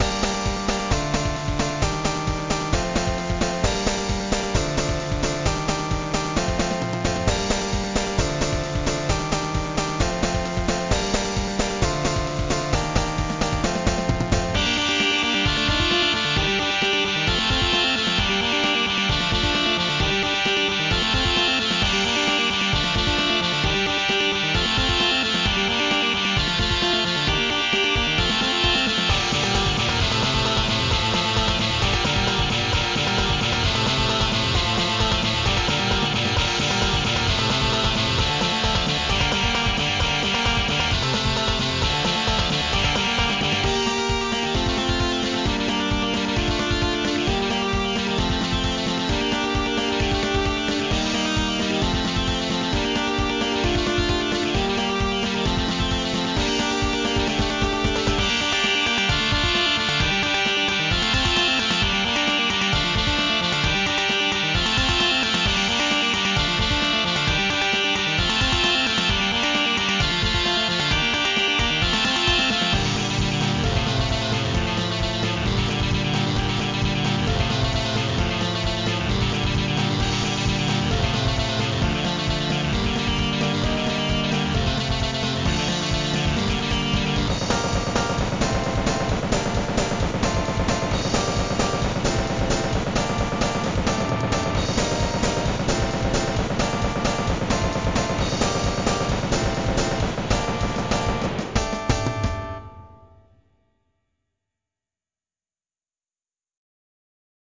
インスト曲